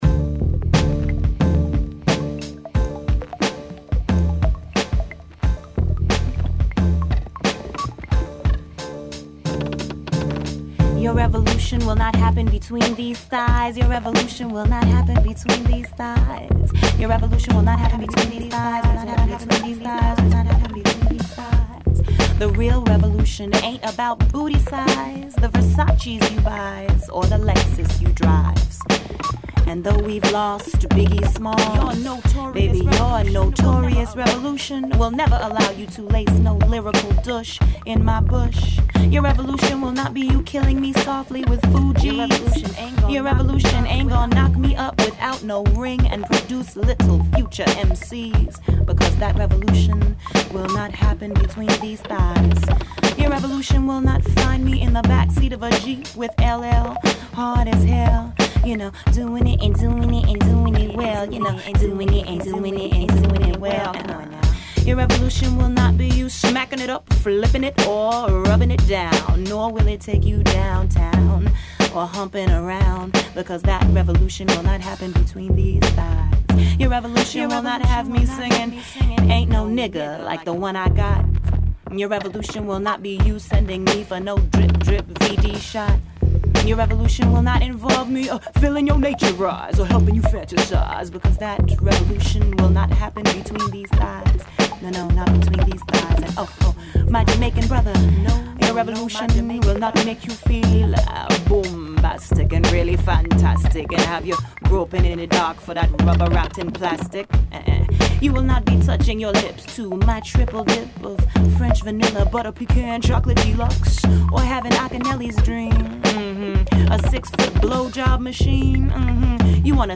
(Various female voices)